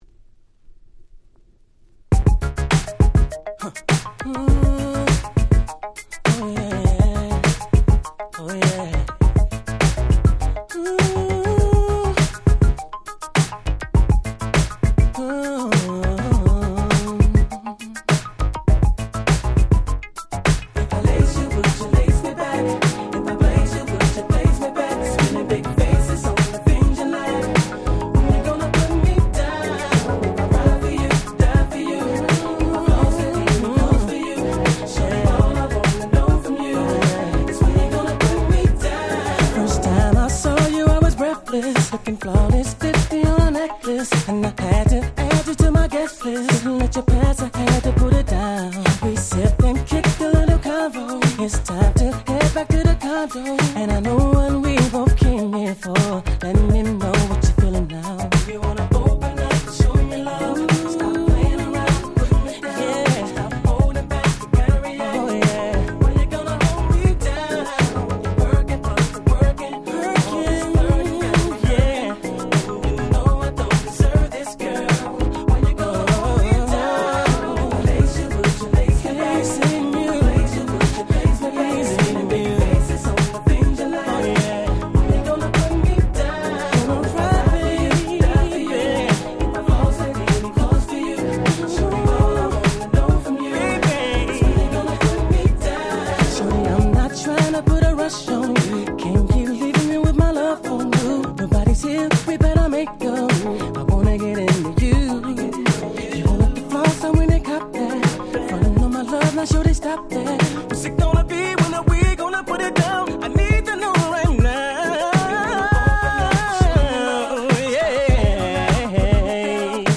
02' Smash Hit R&B !!
こういうSmoothな正当派R&Bはこれからも大切にしていきたいですね。